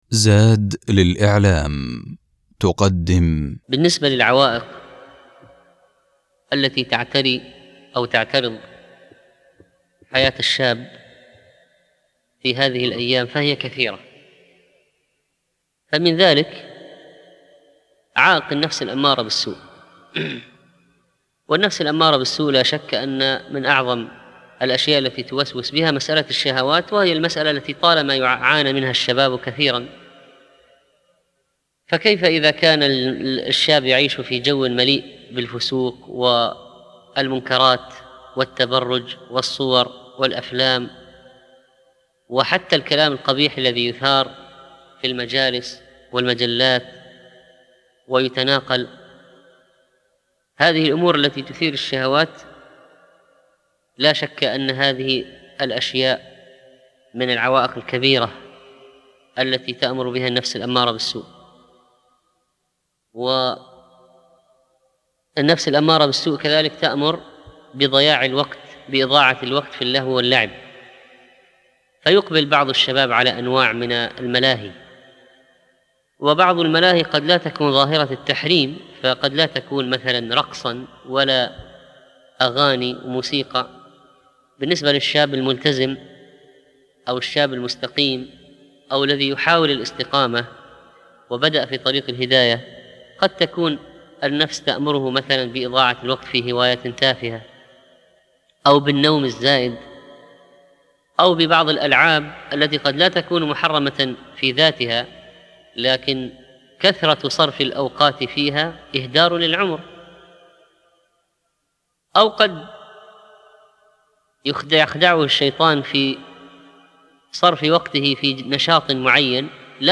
المحاضرات
لقاءات مع الشباب 4 في هذه المحاضرة يجيب الشيخ على مجموعة أسئلة من الشباب الحاضرين،